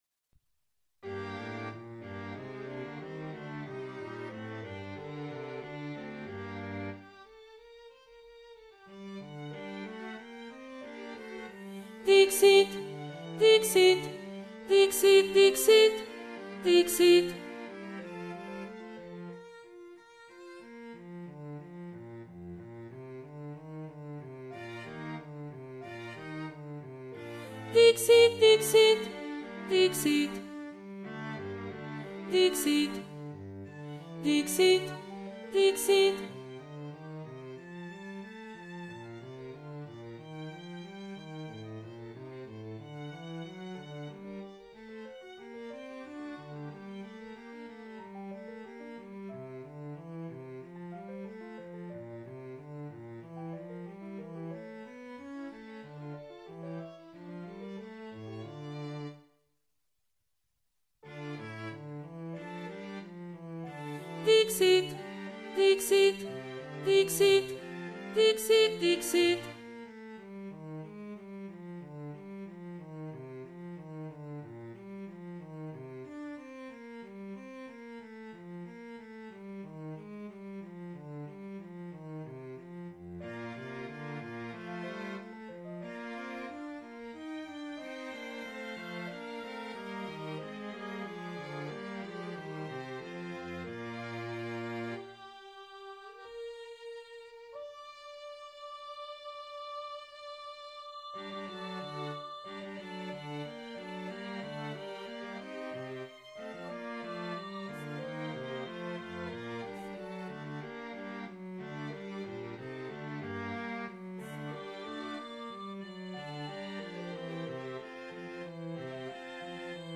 Altos
Emphasised voice and other voices
Dixit-Dominus-Alto-1.mp3